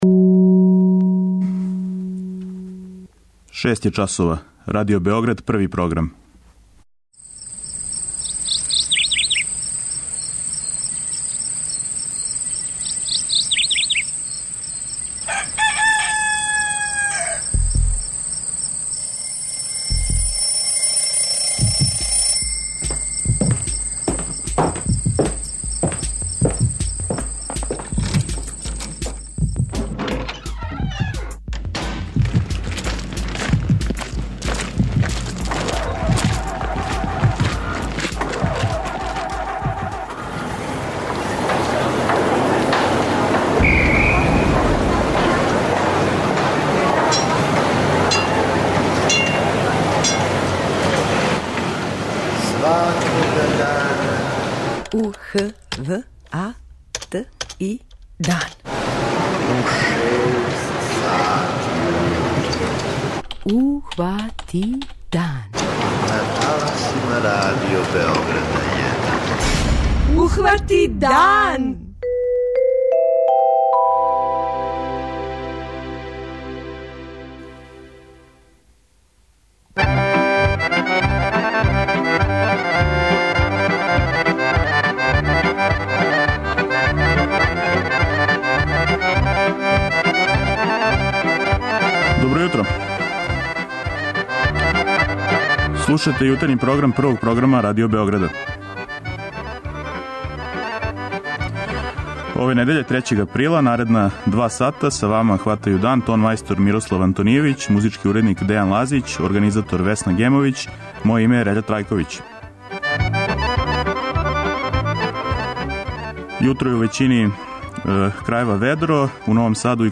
преузми : 57.30 MB Ухвати дан Autor: Група аутора Јутарњи програм Радио Београда 1!